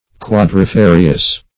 Search Result for " quadrifarious" : The Collaborative International Dictionary of English v.0.48: Quadrifarious \Quad`ri*fa"ri*ous\, a. [L. quadrifarius fourfold, fr. quattuor four: cf. F. quadrifari['e].
quadrifarious.mp3